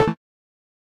Techmino / media / effect / chiptune / key.ogg